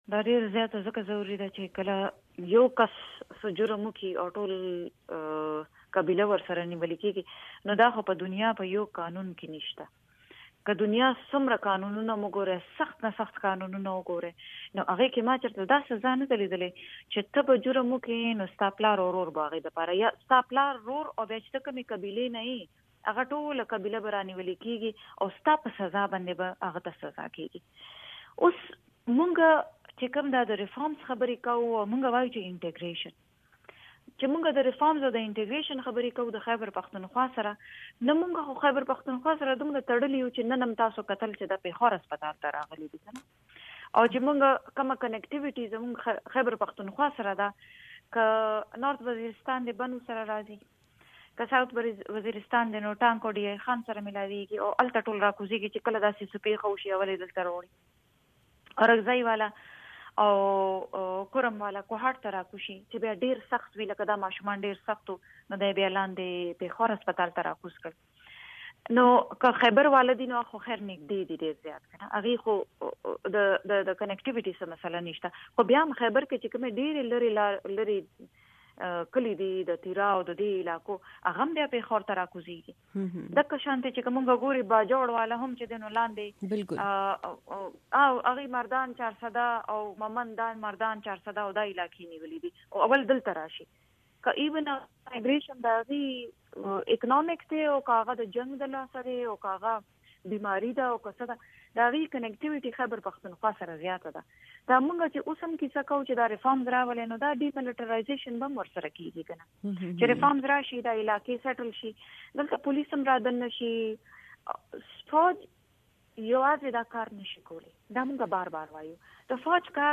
خبرې